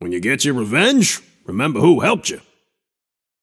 Shopkeeper voice line - When you get your revenge, remember who helped you.